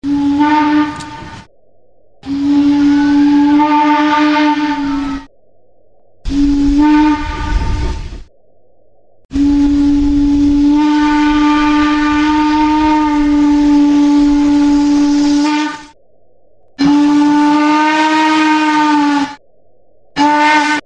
Recorded Whistles for Live Steam Locomotives
American Hooter
whistles_us_hooter.mp3